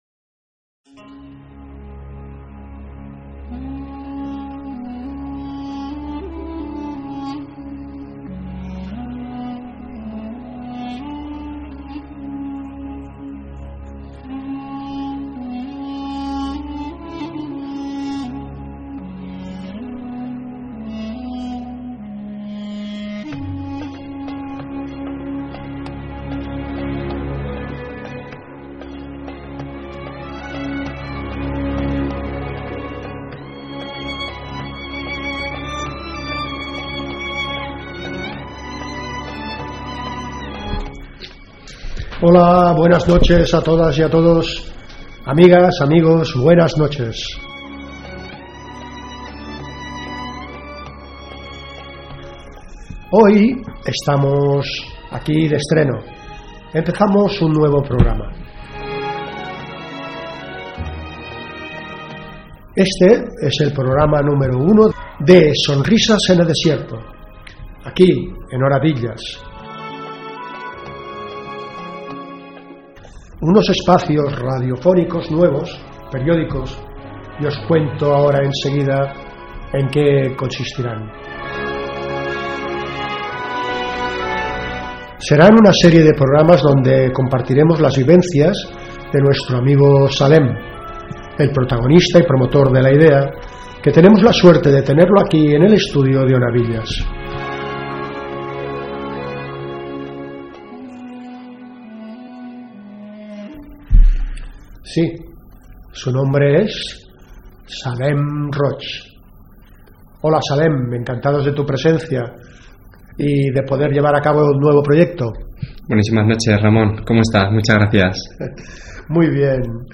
Pública municipal
Entreteniment